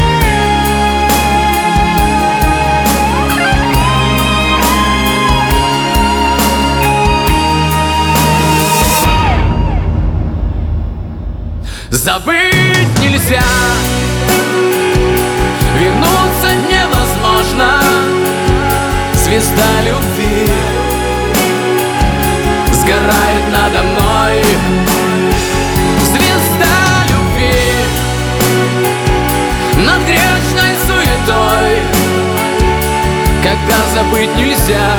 Жанр: Русская поп-музыка / Русский рок / Русские
# Chanson in Russian